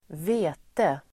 Ladda ner uttalet
Folkets service: vete vete substantiv, wheat Uttal: [²v'e:te] Böjningar: vetet Definition: sädesslaget Triticum vulgare Sammansättningar: vete|mjöl (wheat flour) corn substantiv, vete [i Storbritannien]